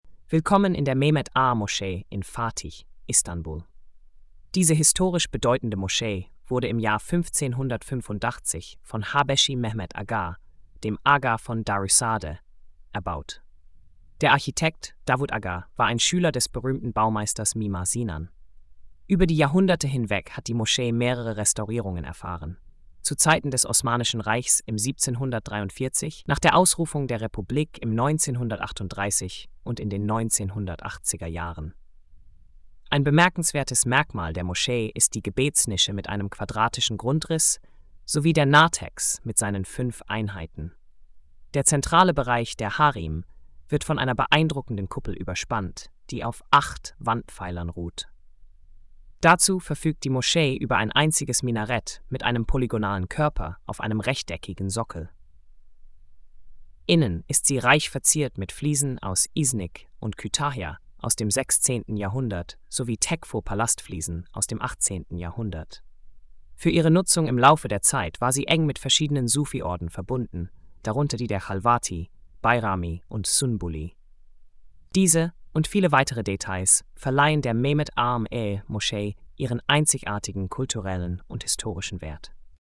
Hörfassung des inhalts: